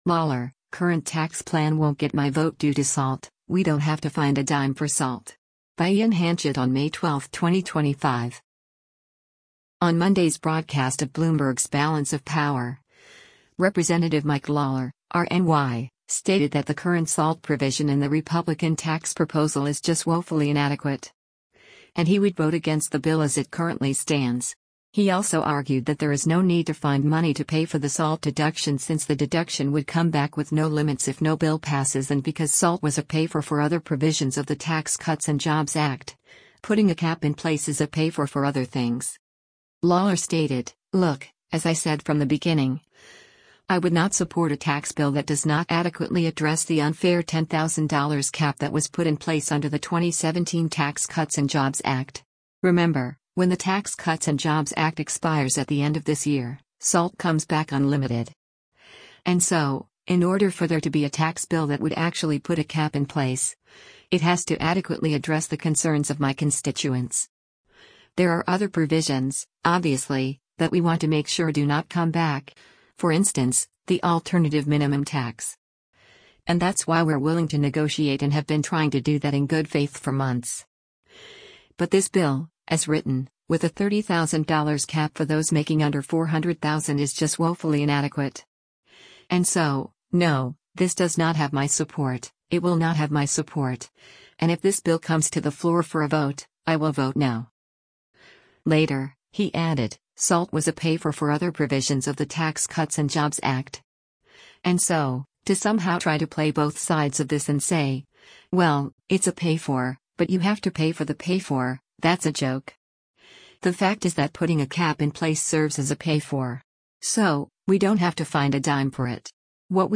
On Monday’s broadcast of Bloomberg’s “Balance of Power,” Rep. Mike Lawler (R-NY) stated that the current SALT provision in the Republican tax proposal “is just woefully inadequate.”